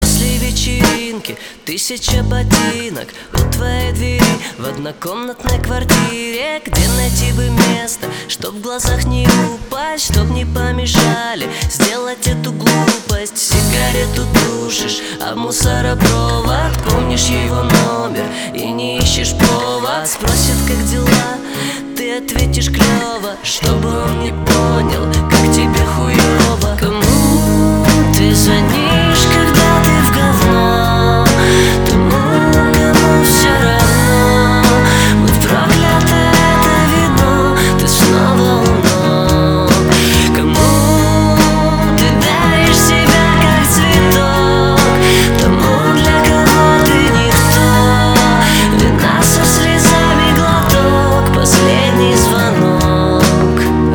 гитара
грустные
красивый мужской голос
лирические
поп-рок